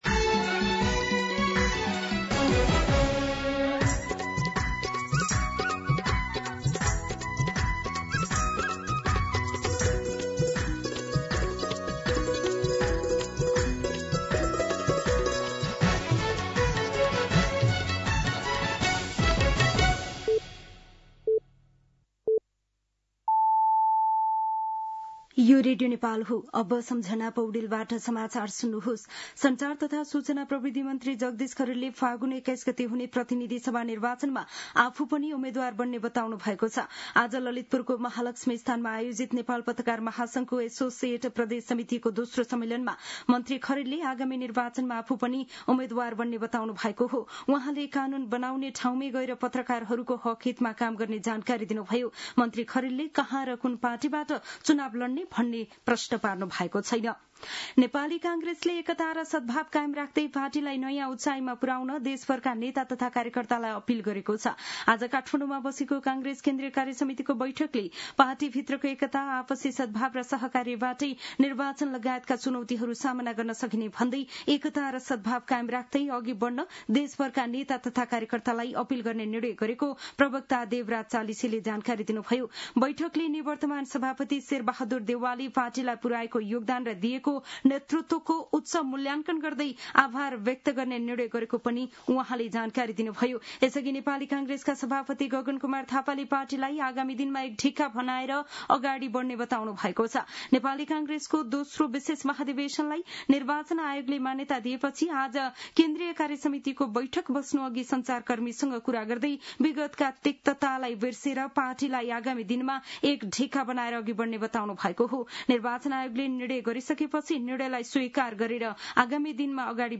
दिउँसो ४ बजेको नेपाली समाचार : ३ माघ , २०८२
4pm-Nepali-News-1.mp3